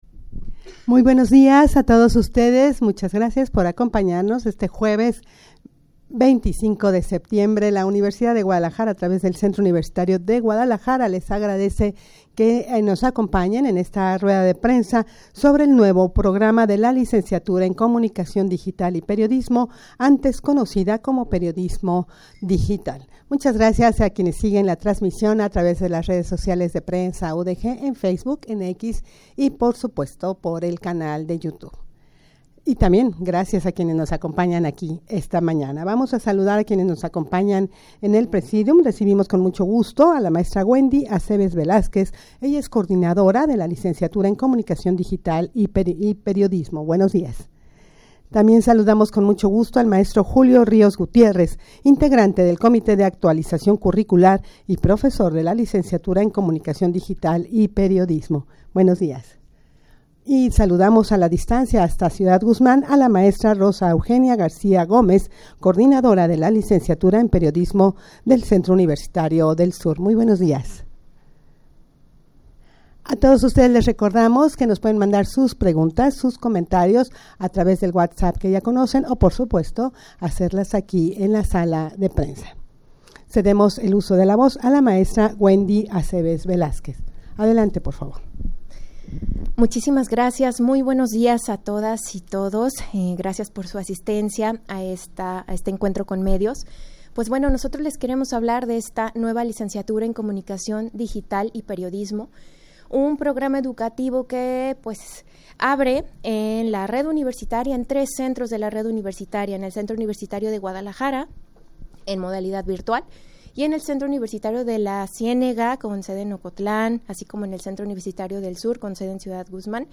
rueda-de-prensa-sobre-el-nuevo-programa-de-la-licenciatura-en-comunicacion-digital-y-periodismo.mp3